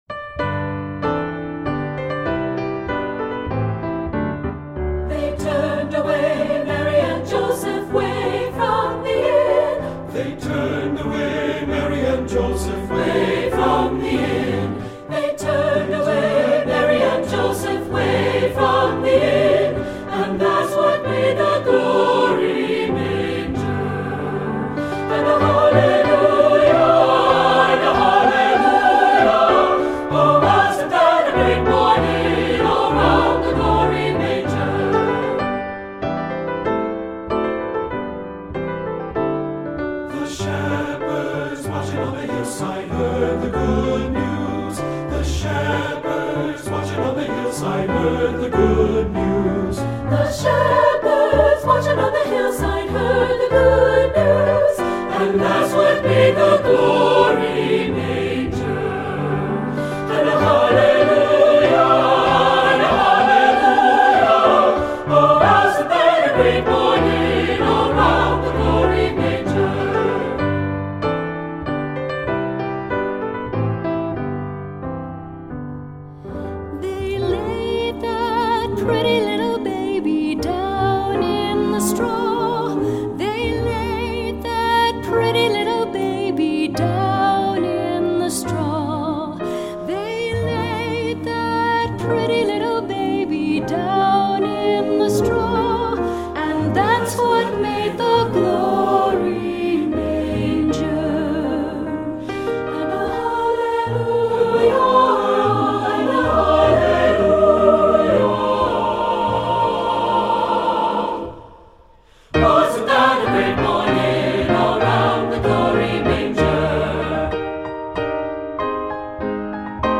Composer: Spiritual
Voicing: SATB and Piano